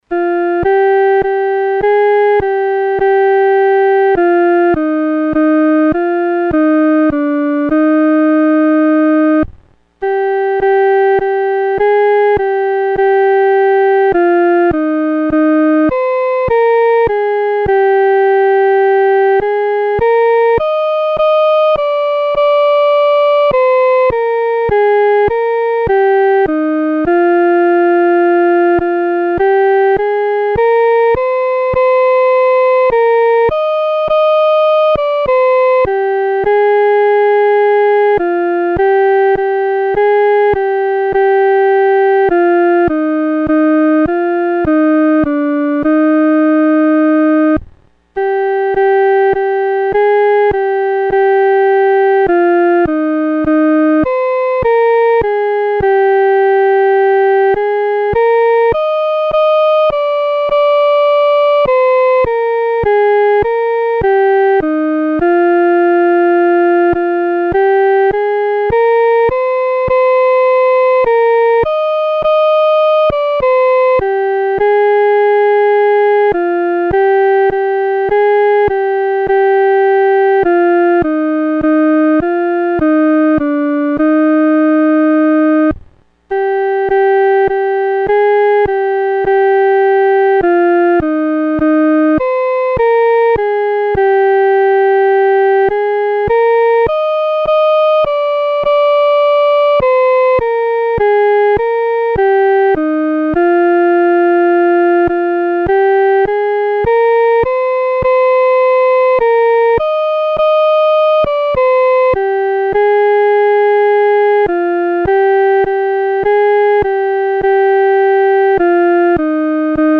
独奏（第一声）
教会维一的根基-独奏（第一声）.mp3